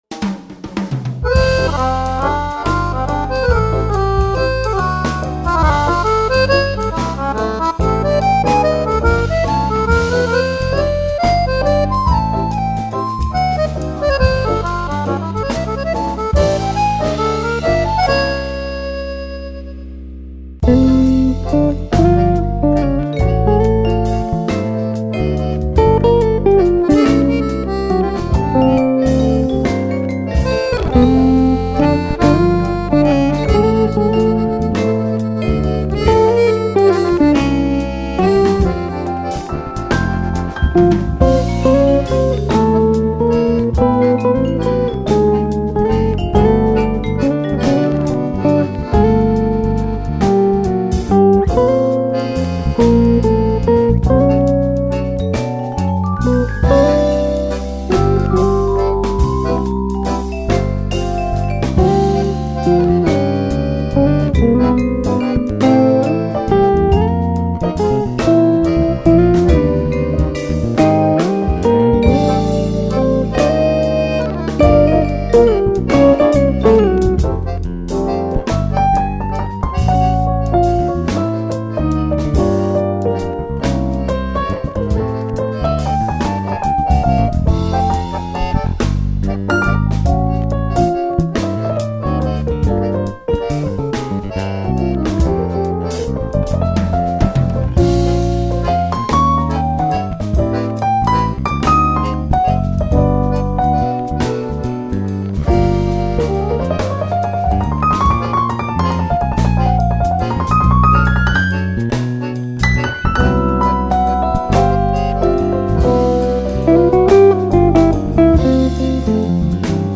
1363   04:19:00   Faixa:     Jazz
Guitarra
Baixo Elétrico 6
Bateria
Teclados
Acoordeon